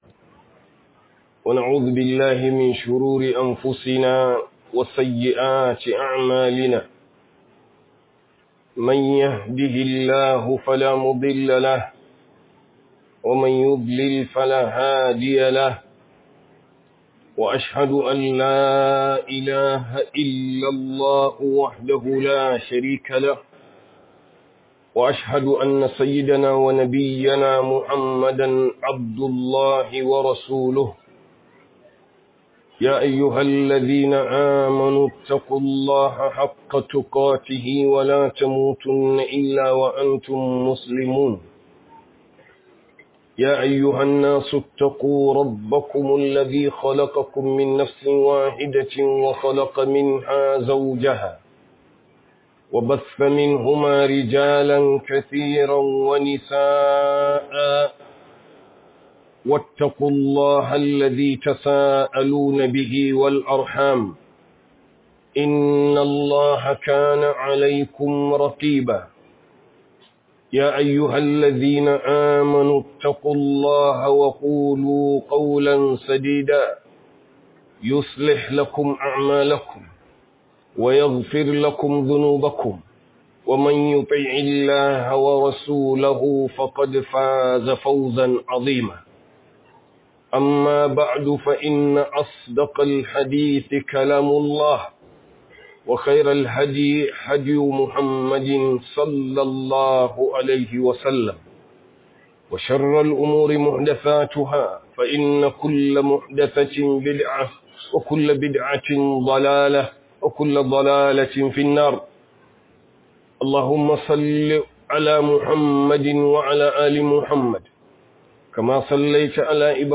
Falalar Gina Masallaci 4 - Huduba